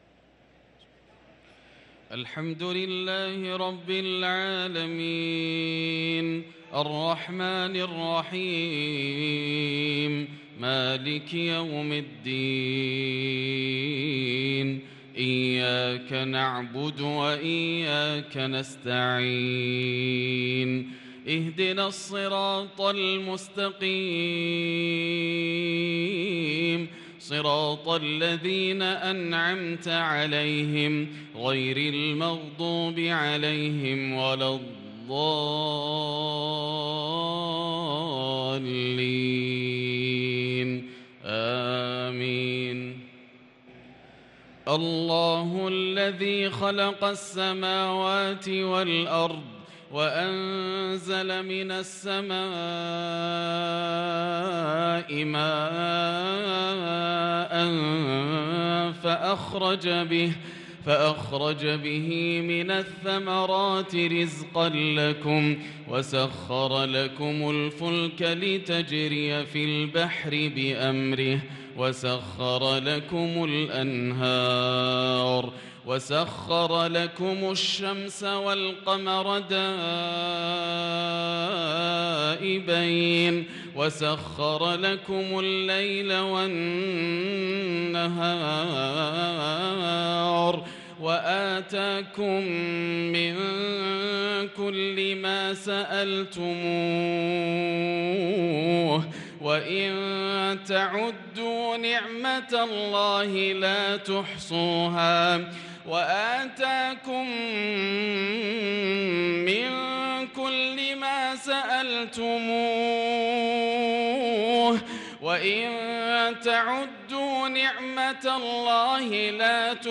صلاة العشاء للقارئ ياسر الدوسري 13 ذو الحجة 1443 هـ
تِلَاوَات الْحَرَمَيْن .